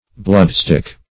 Search Result for " bloodstick" : The Collaborative International Dictionary of English v.0.48: Bloodstick \Blood"stick"\, n. (Far.) A piece of hard wood loaded at one end with lead, and used to strike the fleam into the vein.